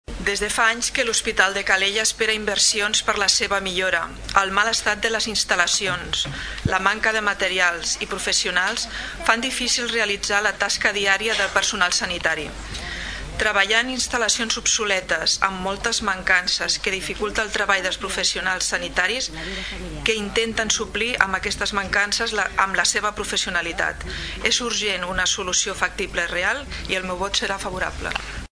La regidora no-adscrita, Sílvia Mateos, es posicionava a favor de la moció, demanant una solució efectiva.